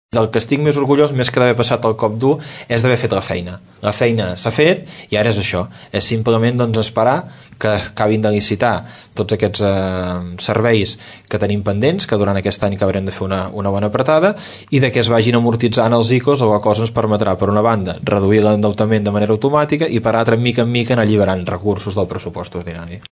El regidor d’Hisenda, Marçal Vilajeliu, assegura en una entrevista a Ràdio Tordera que la situació econòmica de l’Ajuntament està controlada